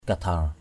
/ka-d̪ʱar/ (d.) thầy Kadhar (nghệ nhân kéo đàn nhị) = officiant musicien Cam.